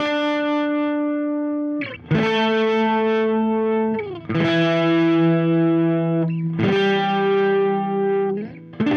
Retro Funkish Guitar 02b.wav